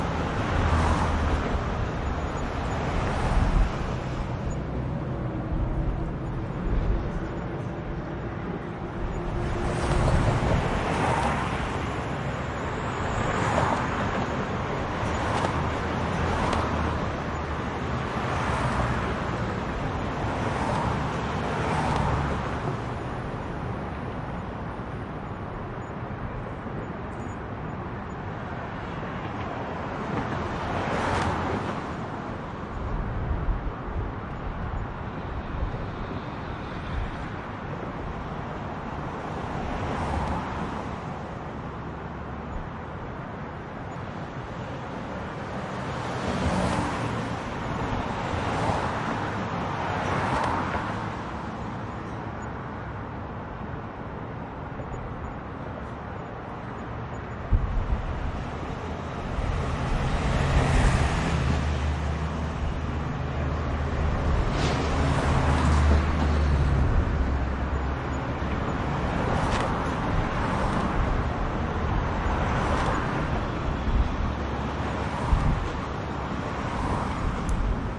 随机 " 交通公路 中型软车 卡车经过分隔带 梅西尔大桥2
Tag: 高速公路 通过软 传球 名士 交通 分频器 汽车 卡车 中型桥